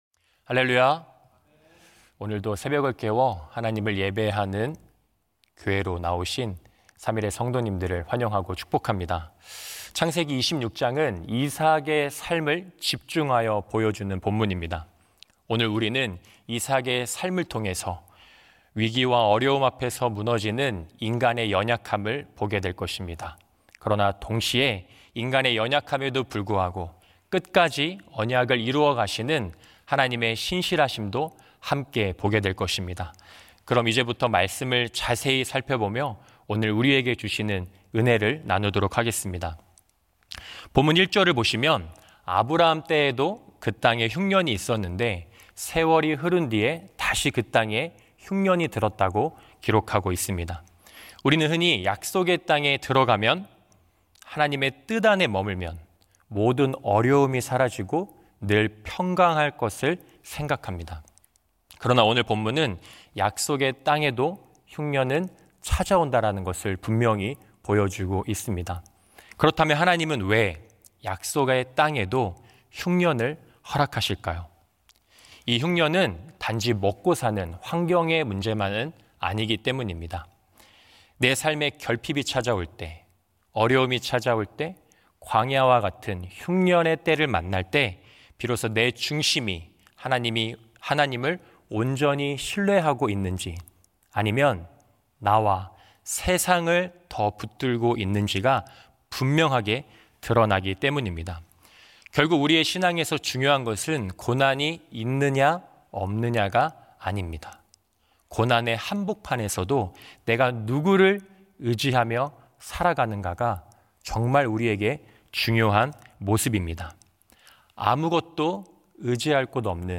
새벽예배